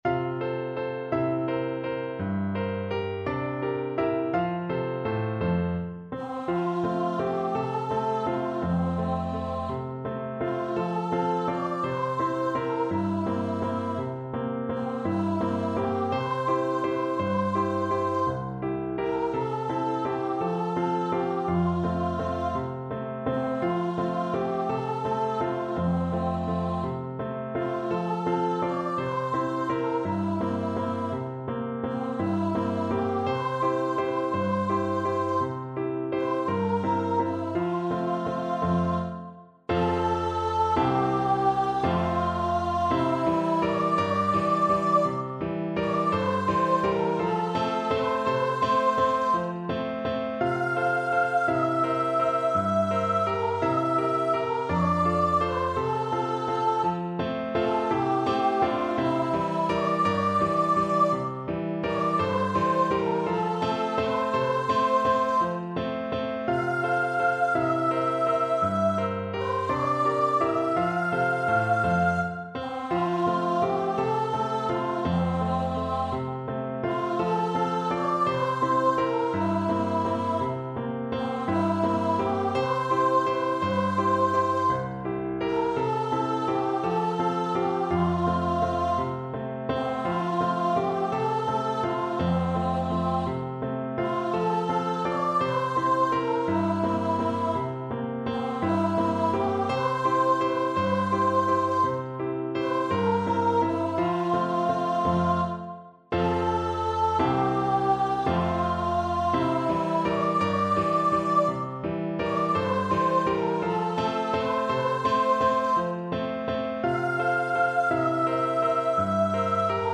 Voice
3/4 (View more 3/4 Music)
F major (Sounding Pitch) (View more F major Music for Voice )
One in a bar =c.168
Traditional (View more Traditional Voice Music)